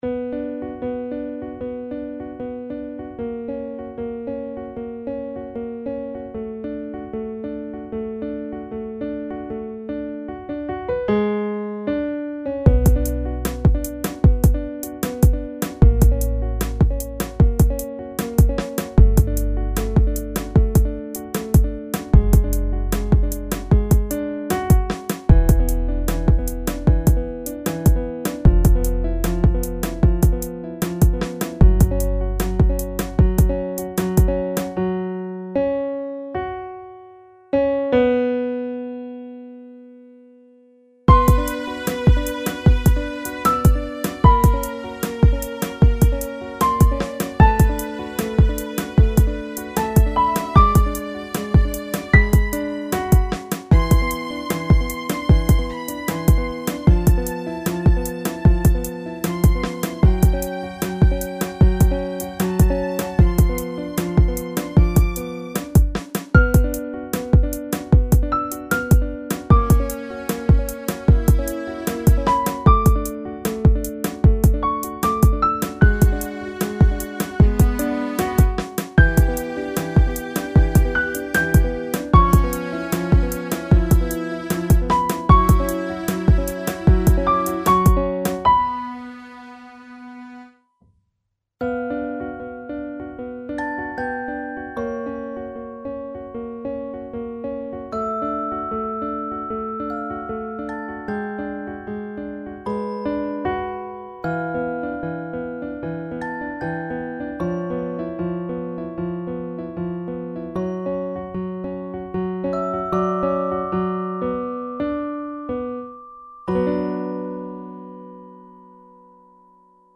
I just sat in front of my keyboard and came out with this.